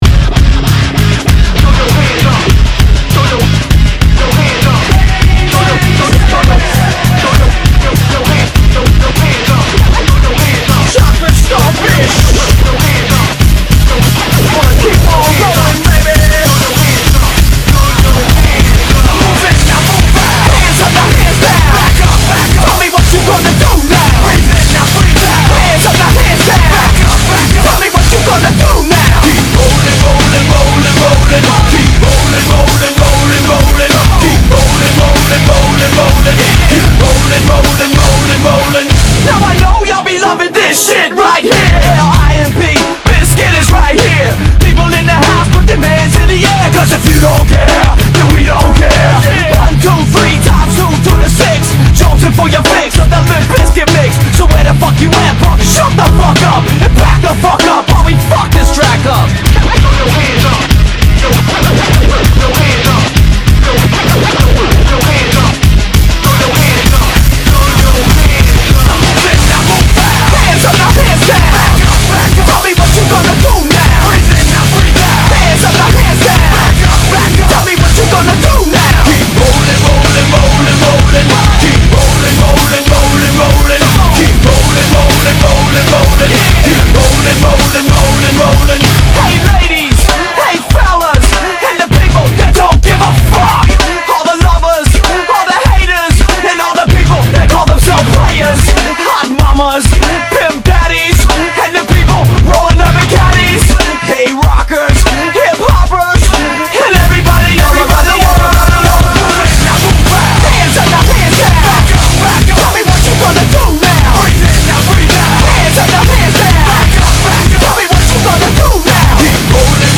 BPM192
Audio QualityCut From Video